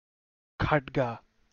Captions English Kannada pronunciation of "khadga"